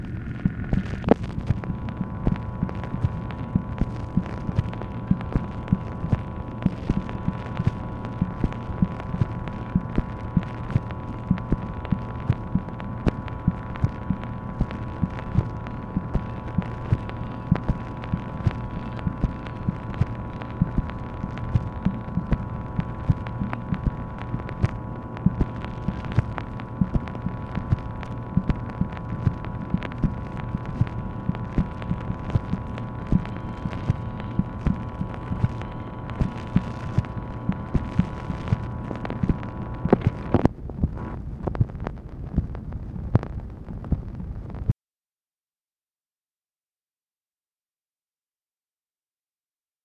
MACHINE NOISE, November 30, 1965
Secret White House Tapes | Lyndon B. Johnson Presidency